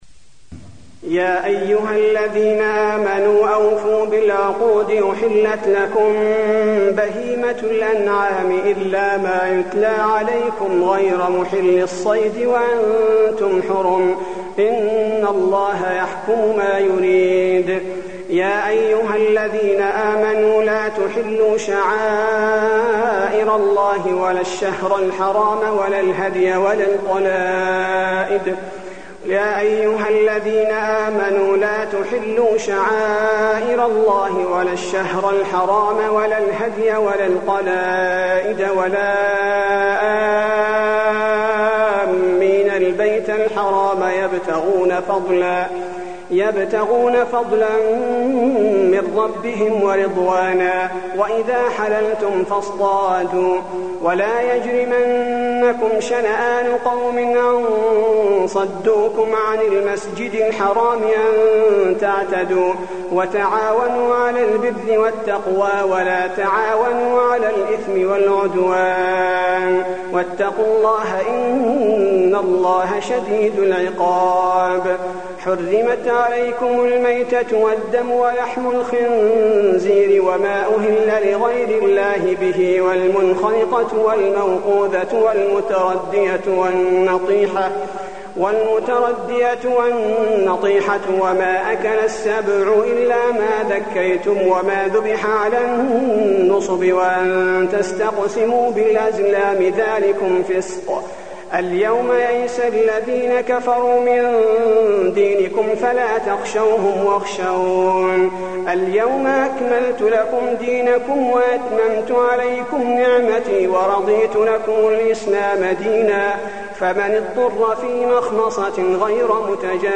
المكان: المسجد النبوي المائدة